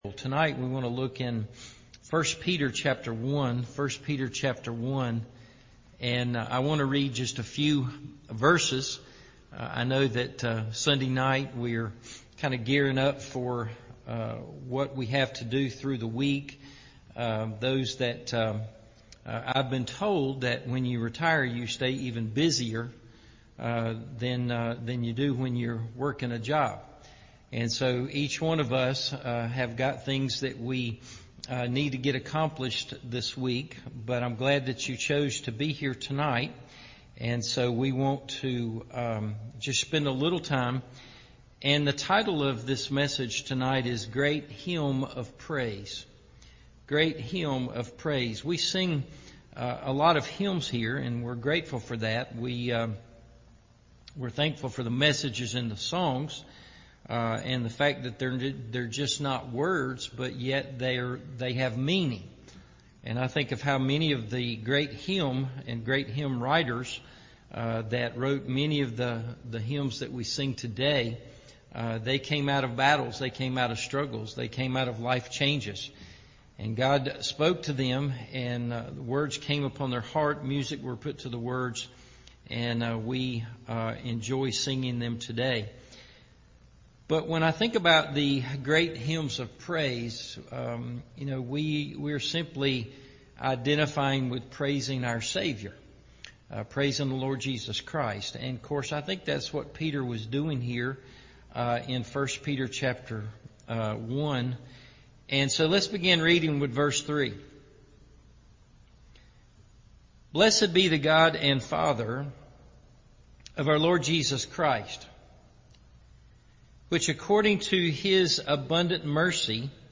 Great Hymn Of Praise – Evening Service